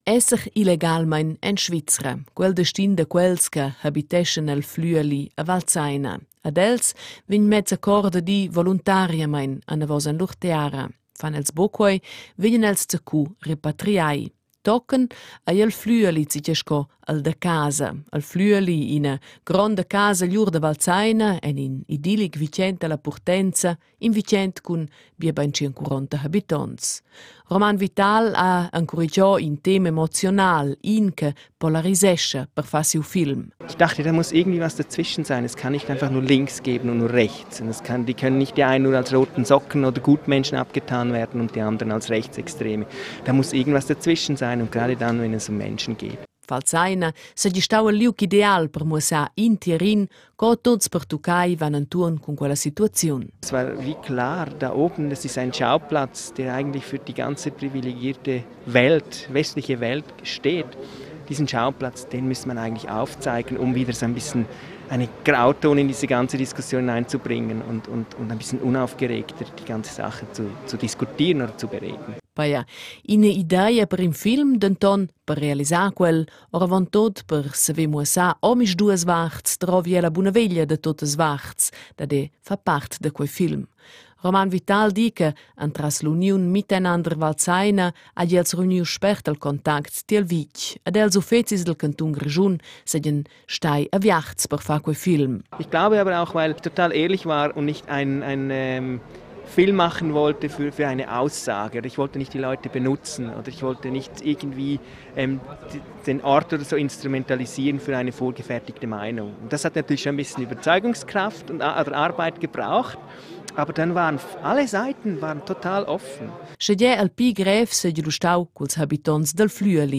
Interview_LifeInParadise.m4a